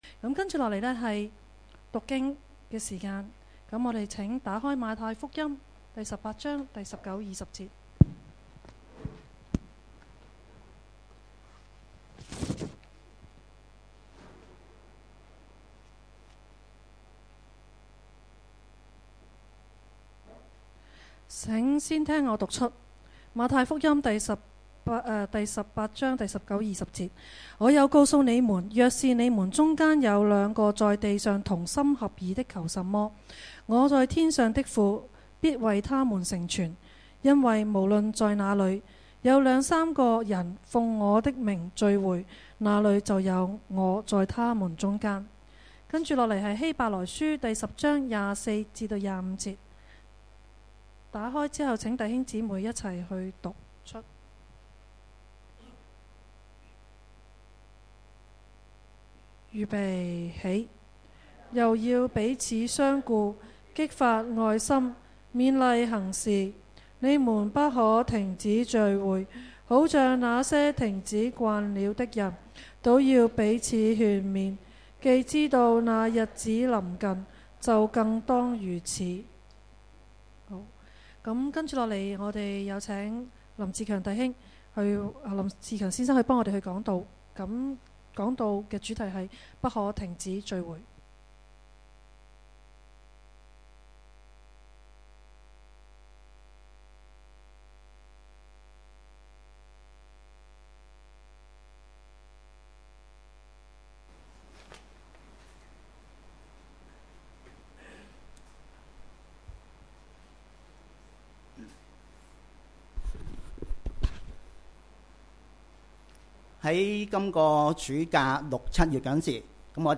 主日崇拜講道-不可停止聚會